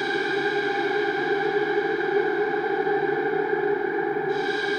Index of /musicradar/sparse-soundscape-samples/Creep Vox Loops
SS_CreepVoxLoopB-10.wav